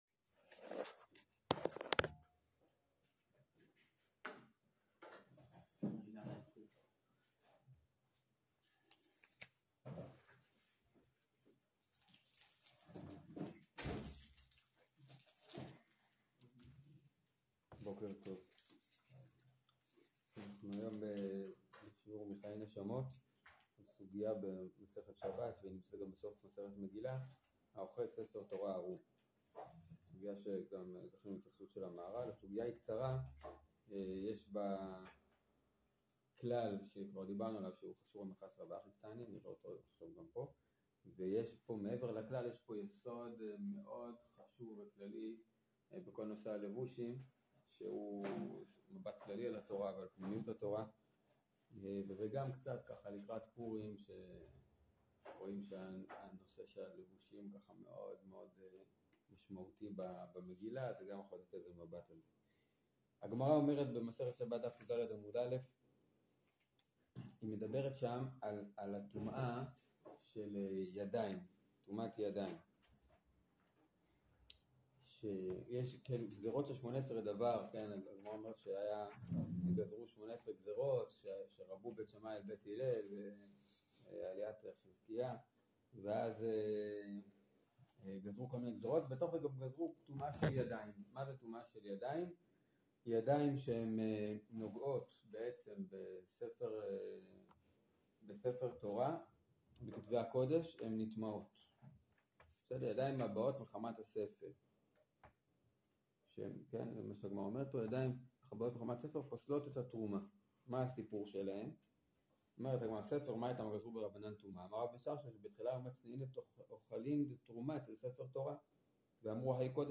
האוחז ספר תורה ערום - מתוך "חיי נשמות אוויר ארצך" 48 דקות 22.4 MB 0:00 0:00 פלוס 10 שניות מינוס 10 שניות 1.0 x מהירות השמעה 1.0 x 0.5 x 1 x 1.5 x 2 x 2.5 x 3 x הורד את קובץ השמע האזנה לשיעור Howler.js